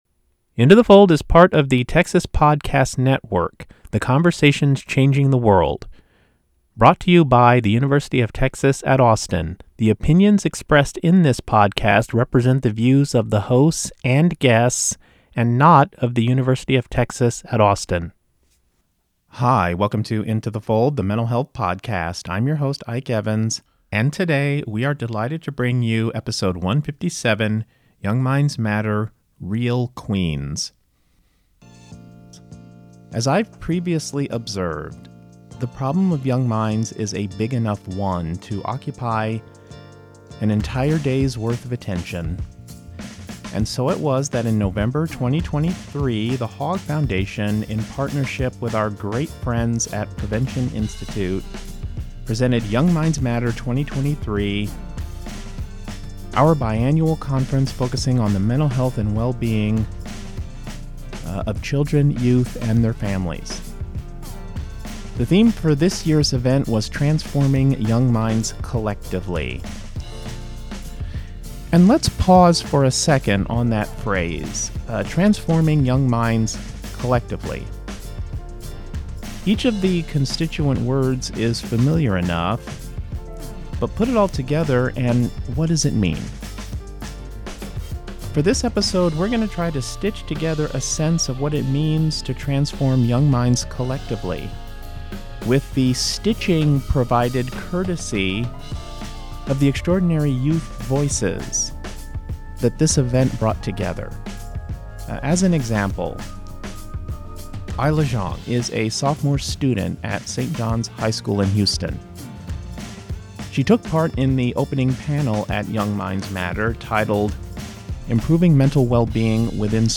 For this episode, we offer a look back at Young Minds Matter 2023!